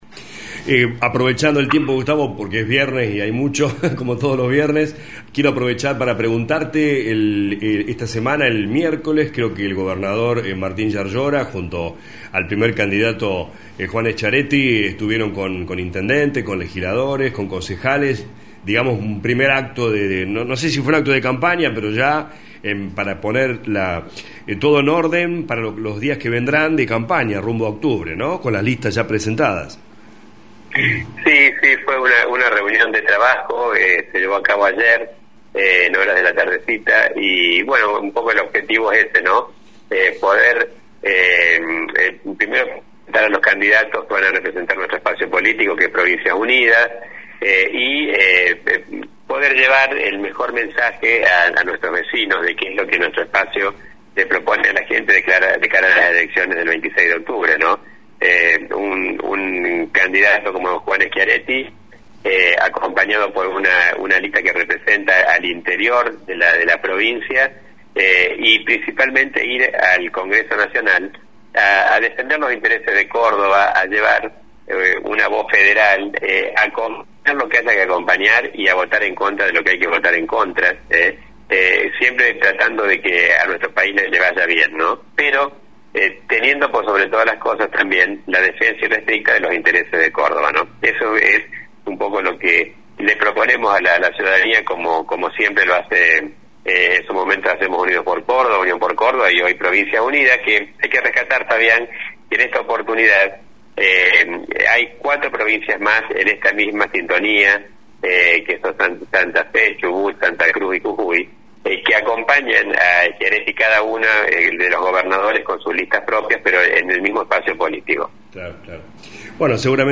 El Legislador Provincial Dr. Gustavo Tevez habló en LA RADIO 102.9 sobre este acto del miercoles y de la campaña que viene por delante.